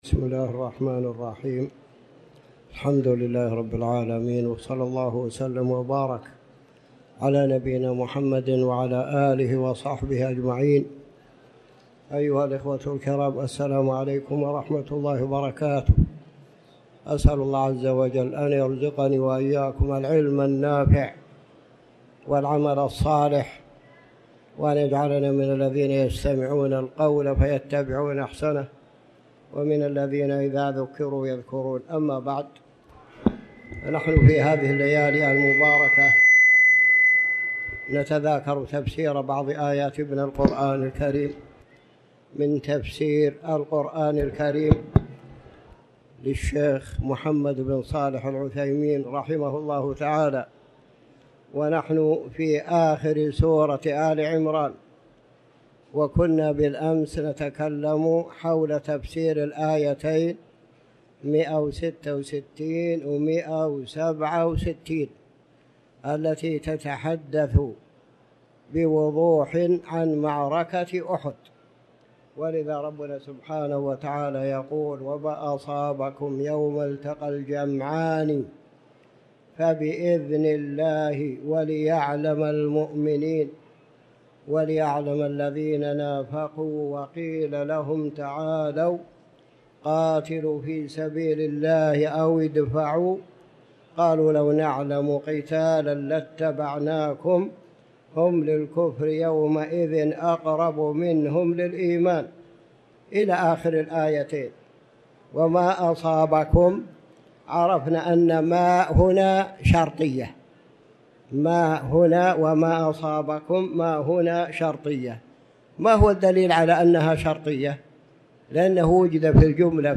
تاريخ النشر ١٧ ربيع الأول ١٤٤٠ هـ المكان: المسجد الحرام الشيخ